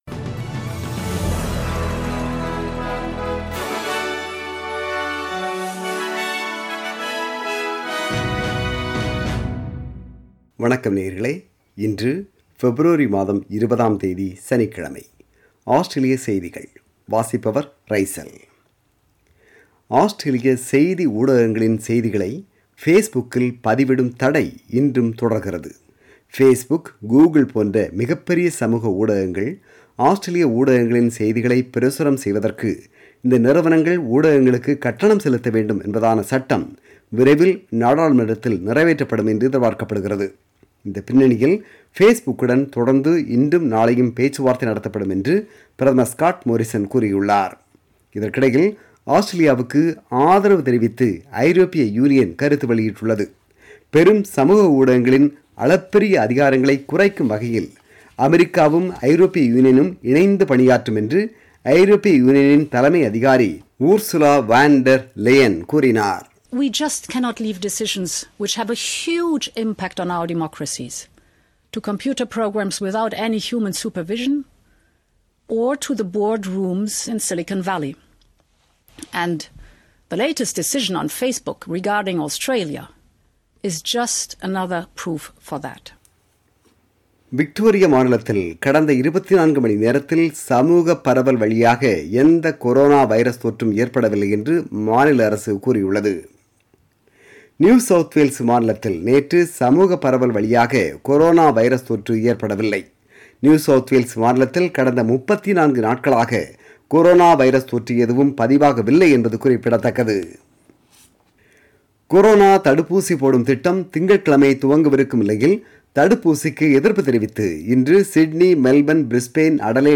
Australian News: 20 February 2021 – Saturday